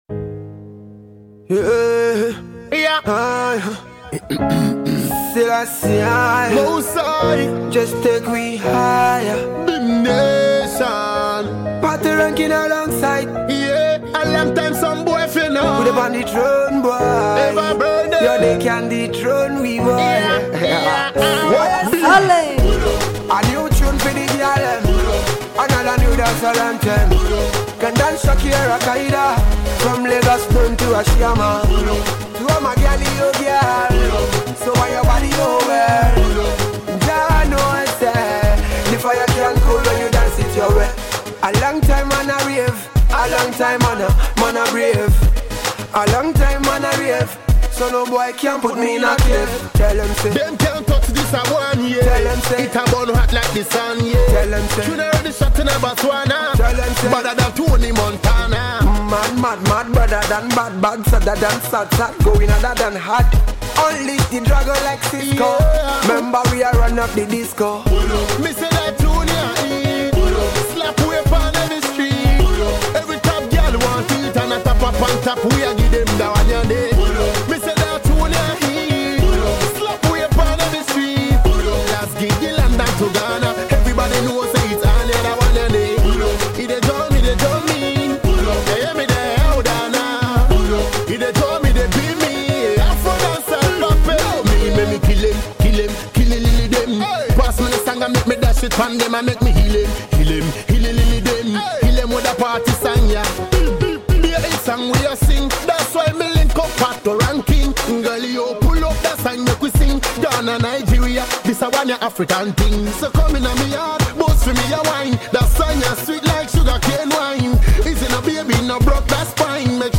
Dancehall
dance-hall